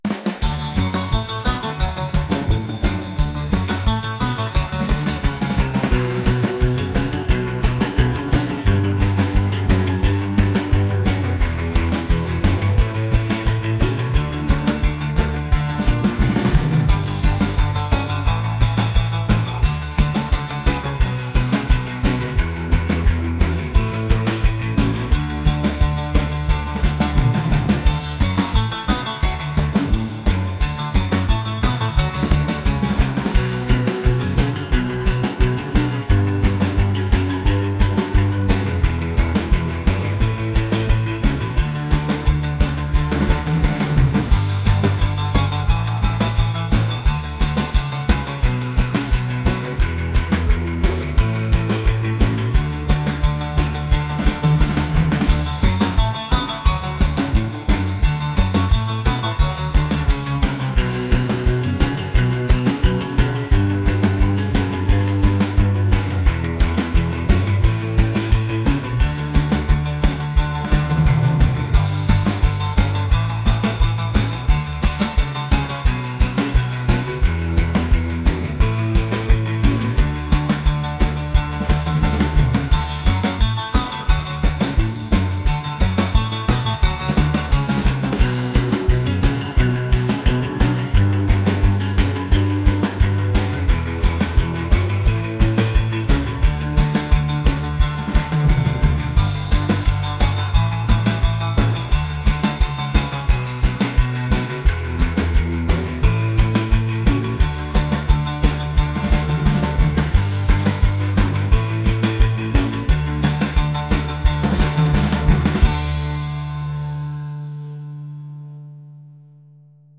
rock | energetic | retro